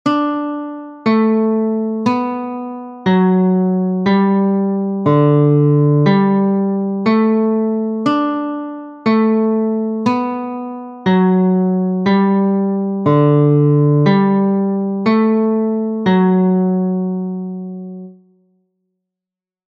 This is a three-voice canon, Pachelbel’s Canon in D major (with F# and C#). The score includes three different instruments (violin, recorder, guitar) so that the three voices can be distinguished.
Third voice.
canon_a_tres_voces_voz3.mp3